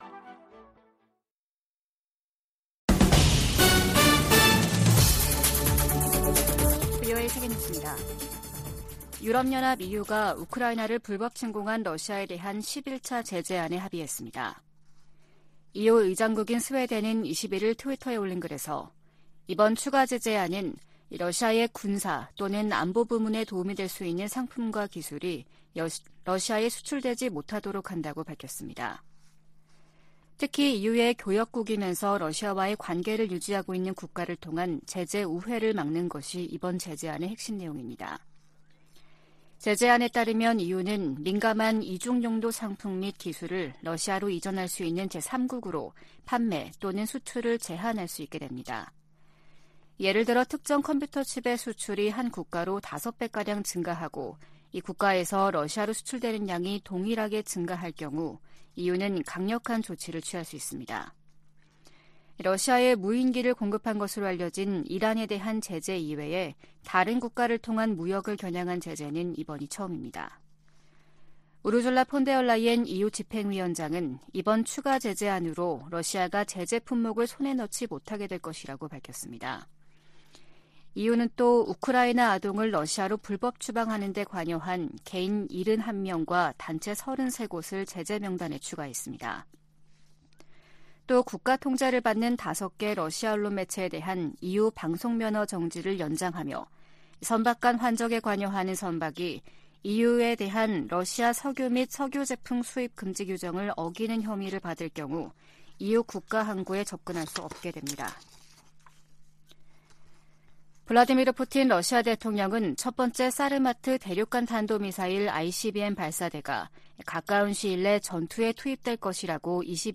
VOA 한국어 아침 뉴스 프로그램 '워싱턴 뉴스 광장' 2023년 6월 22일 방송입니다. 중국은 김정은 북한 국무위원장을 역내에서 가장 불안정한 존재로 인식하고 있다고 블링컨 미 국무장관이 말했습니다. 한국을 방문한 대니얼 크리튼브링크 미국 국무부 동아시아 태평양 담당 차관보는 중국이 북한을 협상 테이블로 나오게 할 책임이 있다고 강조했습니다. 윤석열 한국 대통령과 에마뉘엘 마크롱 프랑스 대통령이 정상회담을 갖고 북한 위협에 맞서 협력하기로 했습니다.